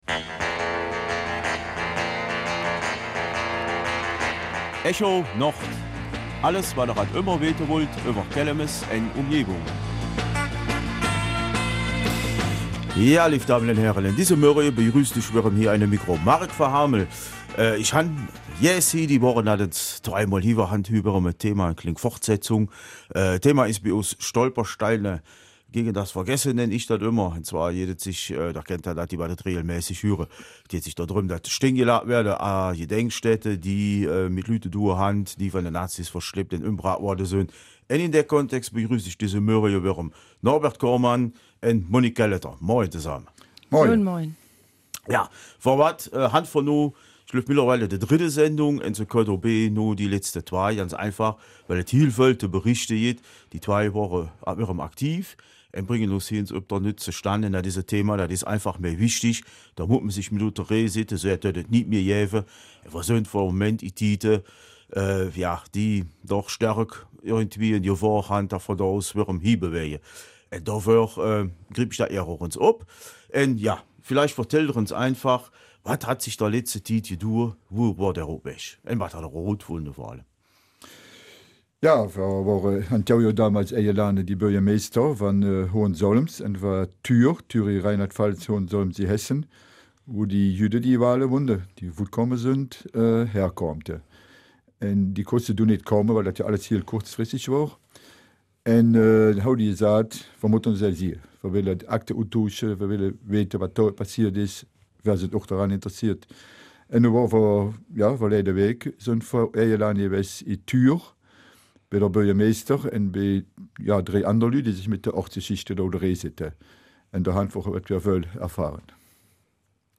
Kelmiser Mundart: Stolpersteine gegen das Vergessen - Teil 3
In der Reihe ''Stolpersteine gegen das Vergessen'' berichten die beiden Gäste diese Woche über einen Besuch in Thür, einer Ausstellung im Atelier I.S. sowie über verschiedene Eindrücke, die ihr Projekt auf persönlicher Ebene mit sich bringt.